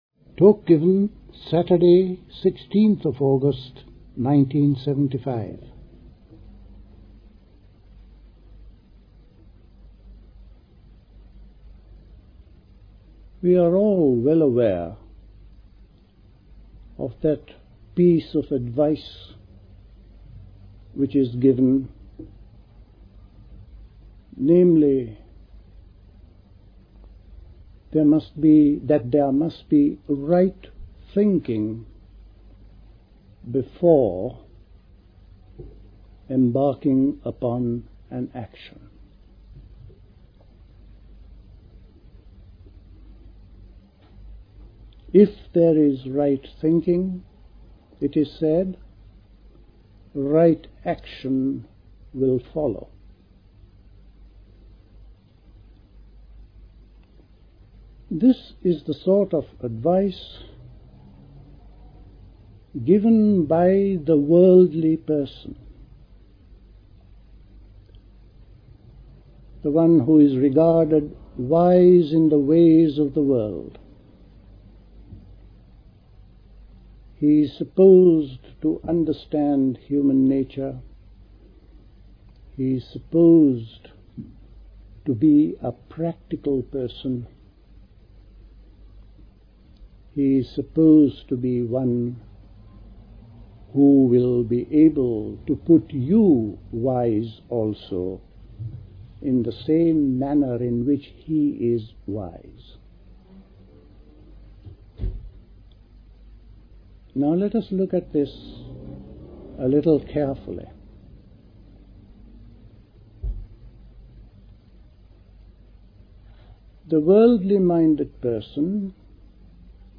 A talk
Dilkusha, Forest Hill, London on 16th August 1975